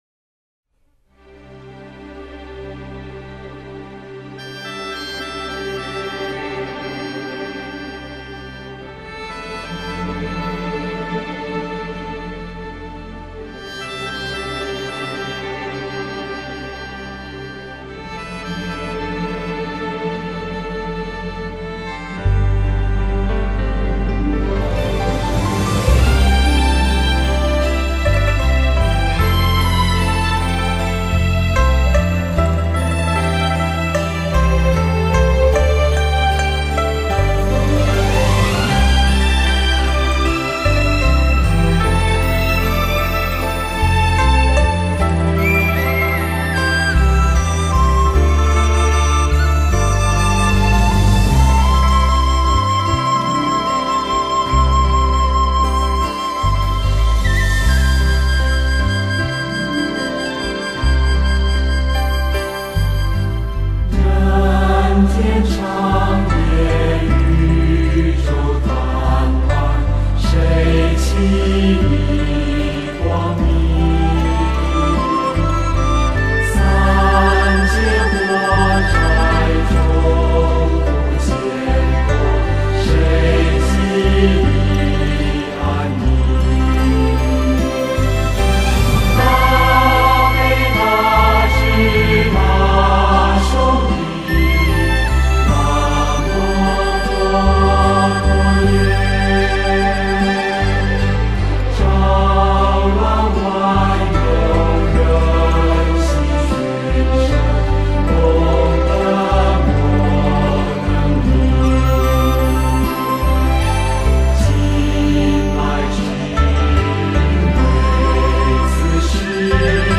《三宝歌》佛教音乐